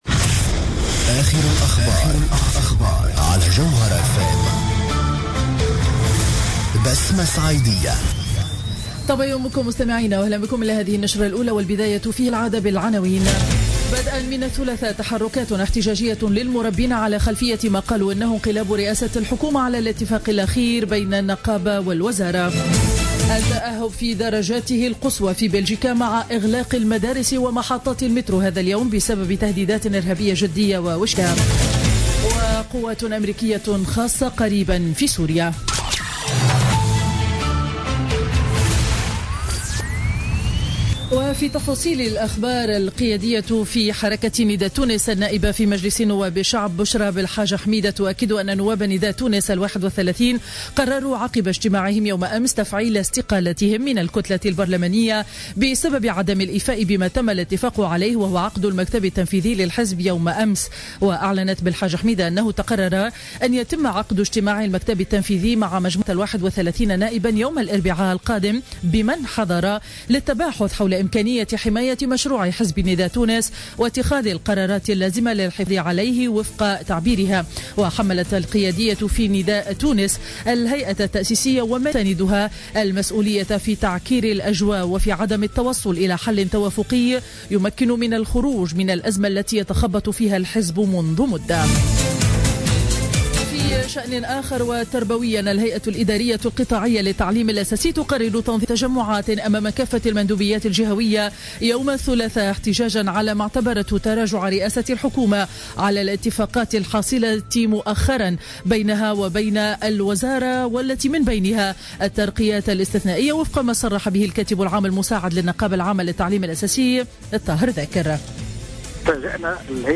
نشرة أخبار السابعة صباحا ليوم الاثنين 23 نوفمبر 2015